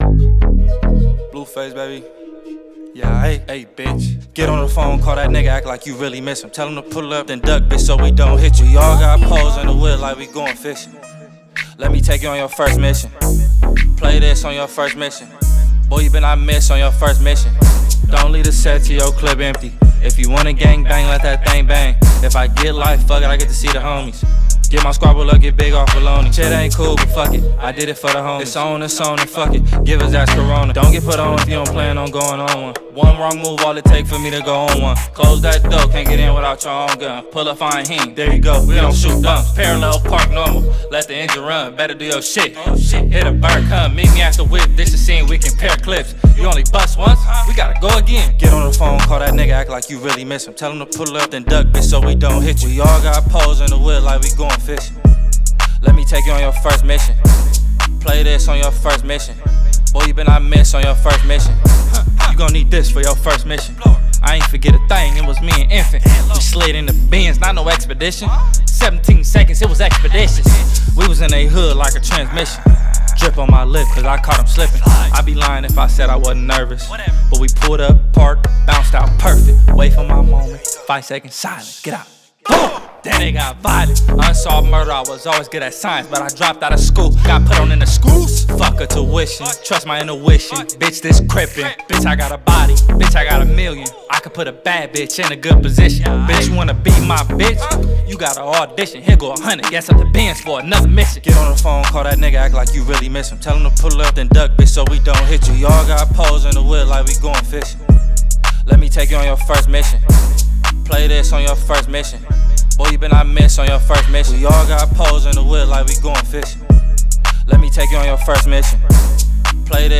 American rapper